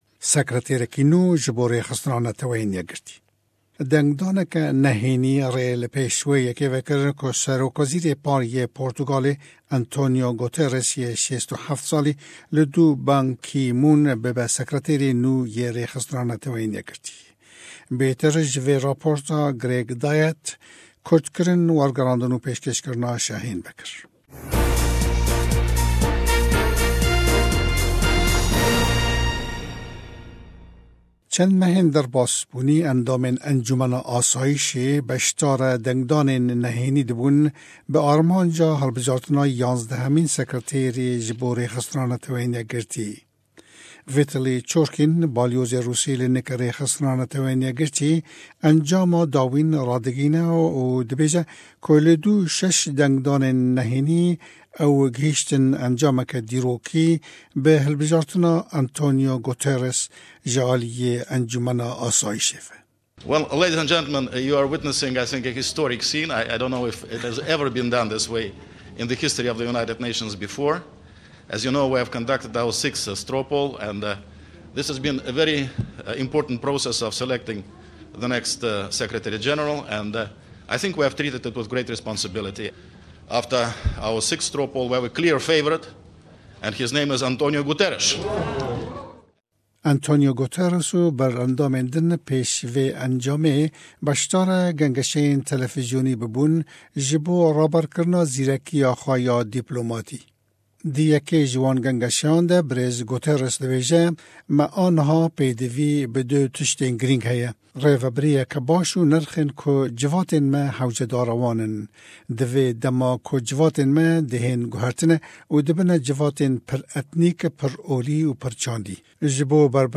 Bêtir ji vê raporta me.